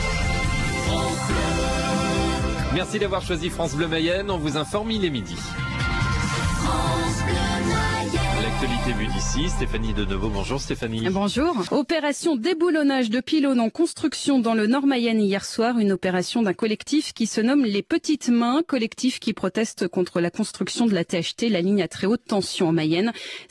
Le flash-info de midi de France Bleu Mayenne le 31 janvier 2012